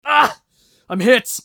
eft_usec_wound11.mp3